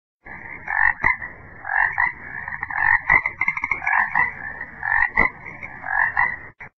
Прикольные звонки